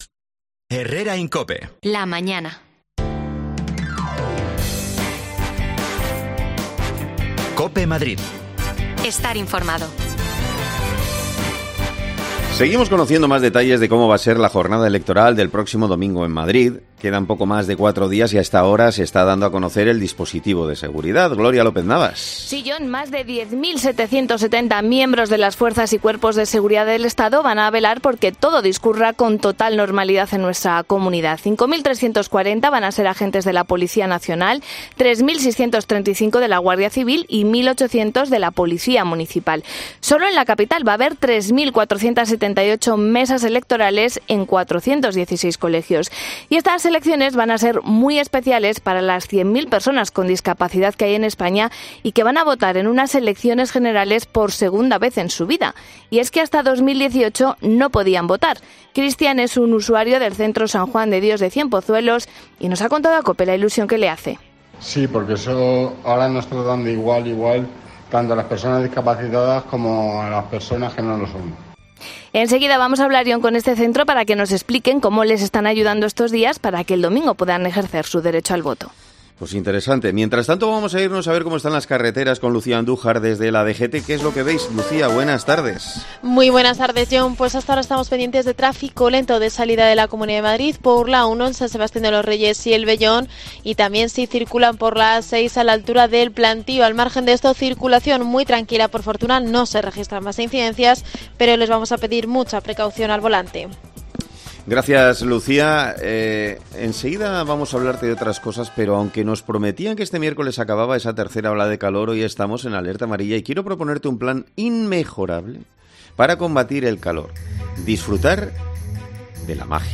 El 23 de julio será la segunda vez que las peronas con discapacidad puedan hacer uso de su derecho a voto. Hablamos con un psicólogo que les ofrece la formación necesaria
Las desconexiones locales de Madrid son espacios de 10 minutos de duración que se emiten en COPE, de lunes a viernes.